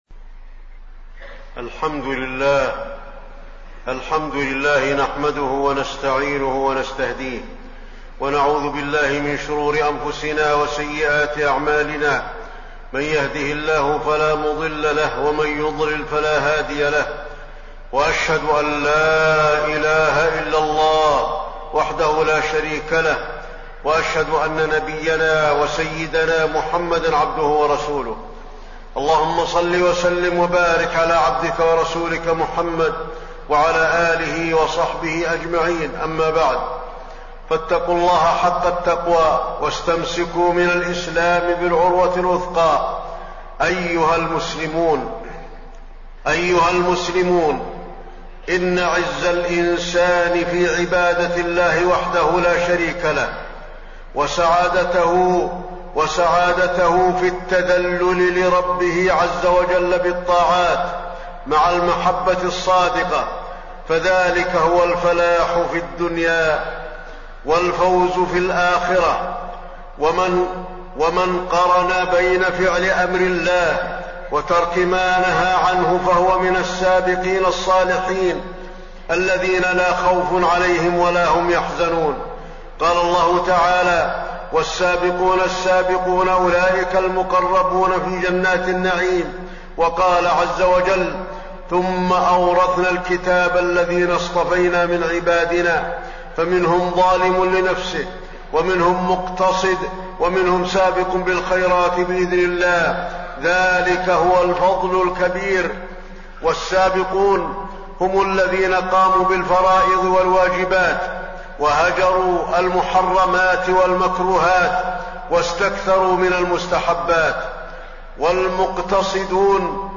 تاريخ النشر ١١ شوال ١٤٣٢ هـ المكان: المسجد النبوي الشيخ: فضيلة الشيخ د. علي بن عبدالرحمن الحذيفي فضيلة الشيخ د. علي بن عبدالرحمن الحذيفي أسباب الفلاح في الدارين